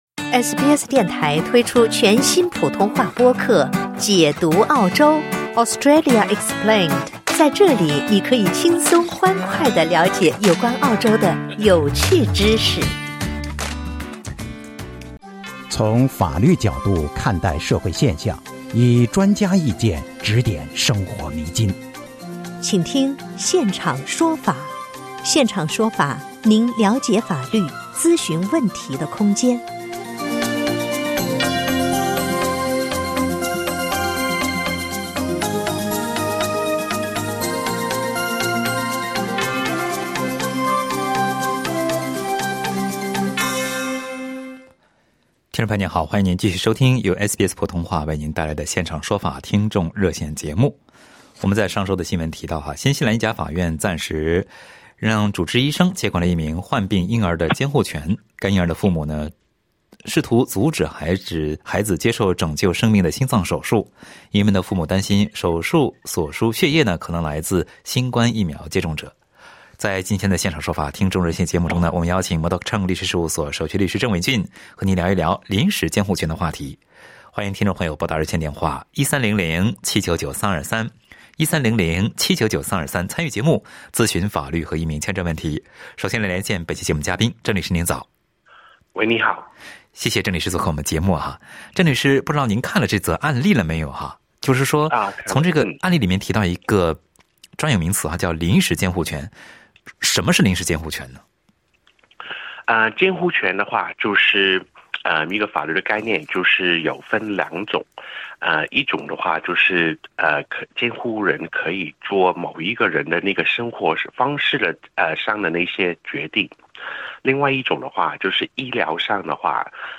在本期《现场说法》热线节目中